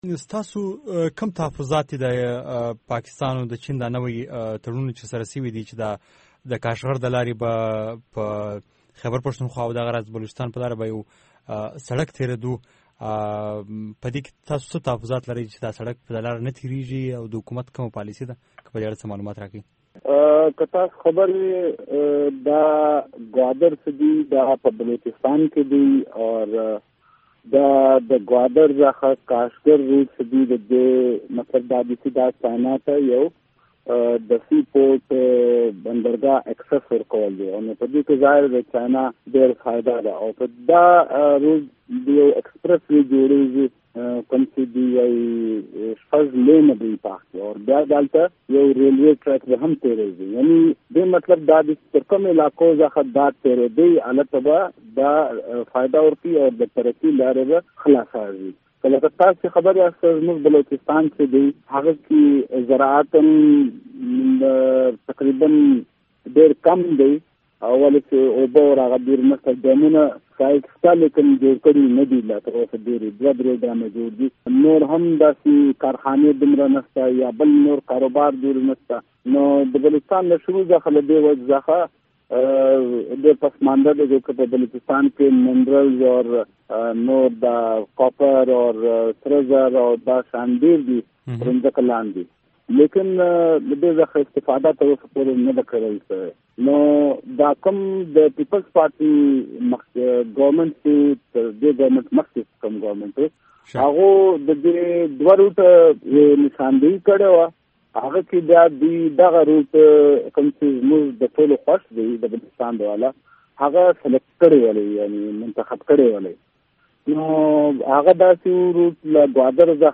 د آشنا رادیو بشپړه مرکه